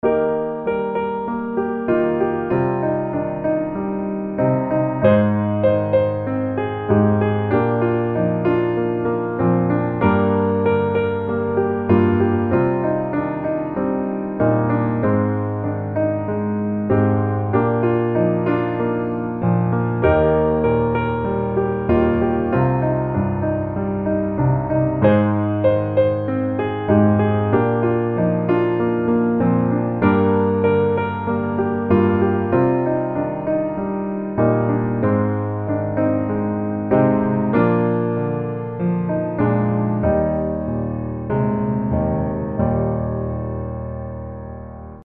Eb Majeur